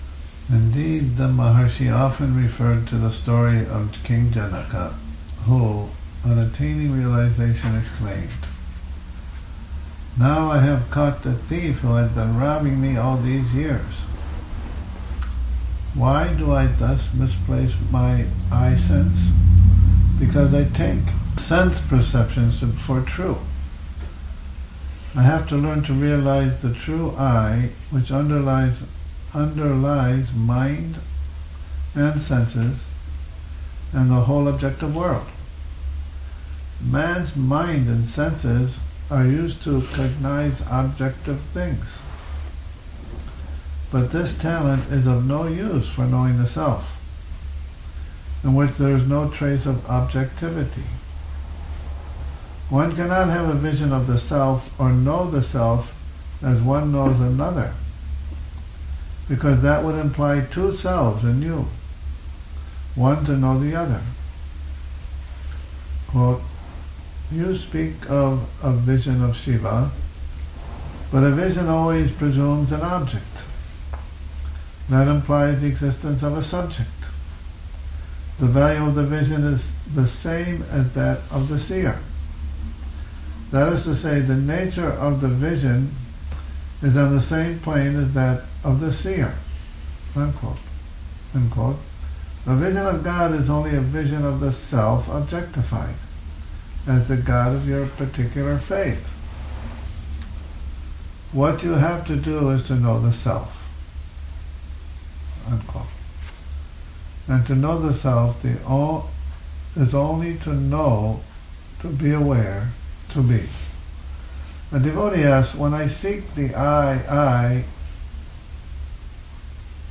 a reading from 'The Mountain Path' Morning Reading, 05 Oct 2019